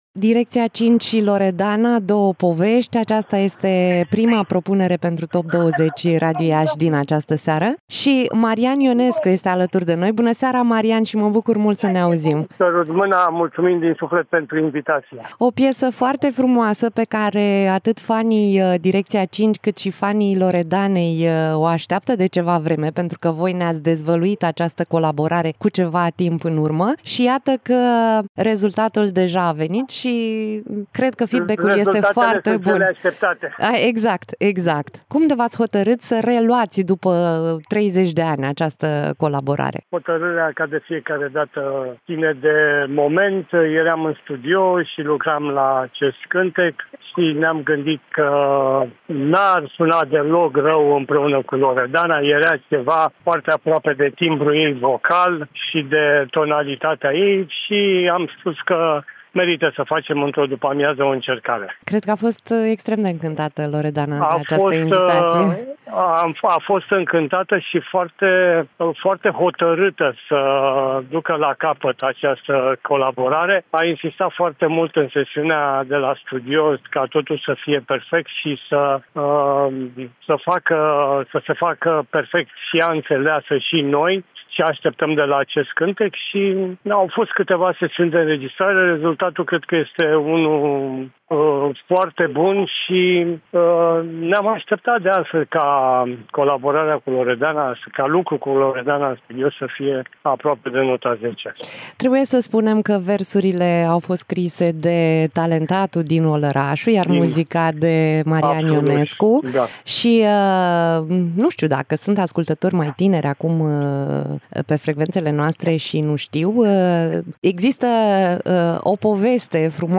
(INTERVIU) Marian Ionescu
Interviu-Marian-Ionescu-Top-20-Radio-Iasi.mp3